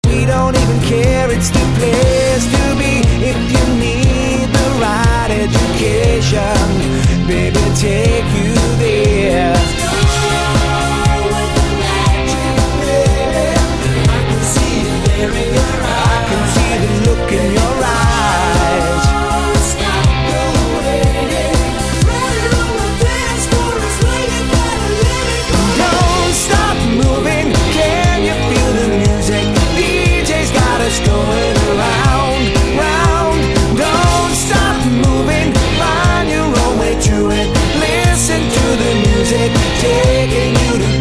cover band